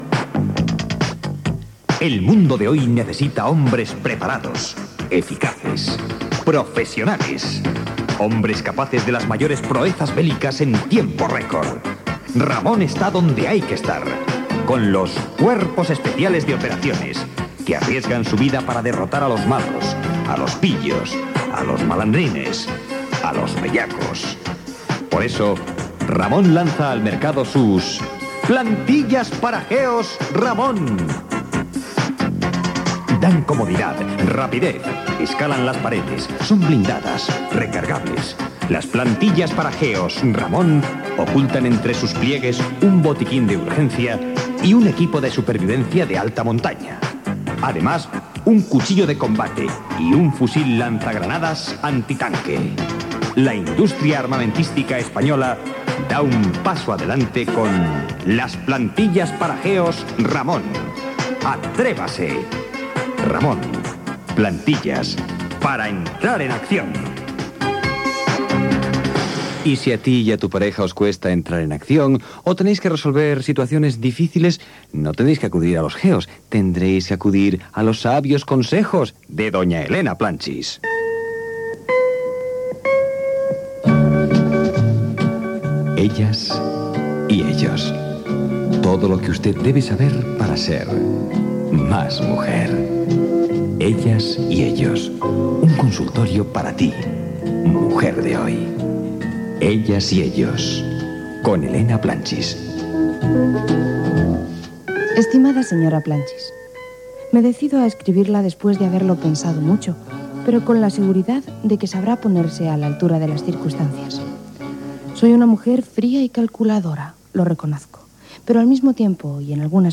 Espai humorístic de publicitat inventada, indicatiu del programa
Entreteniment
FM